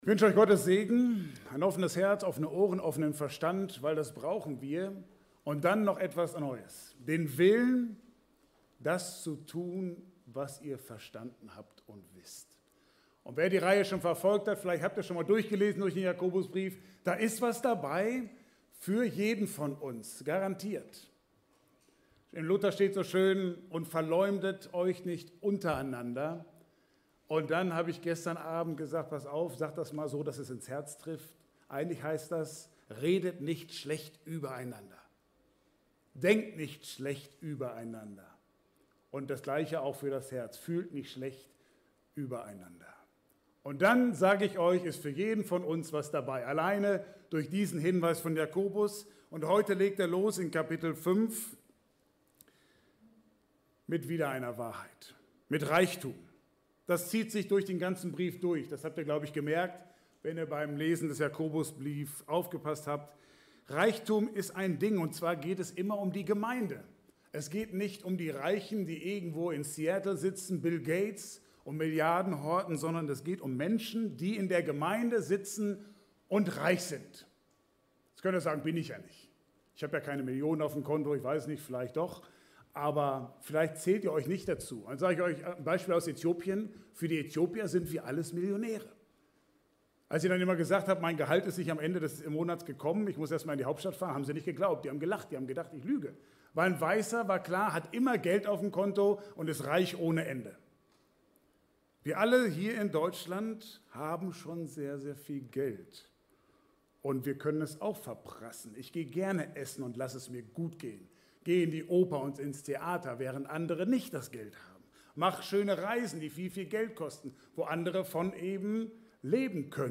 Passage: Jakobus 5 Dienstart: Gottesdienst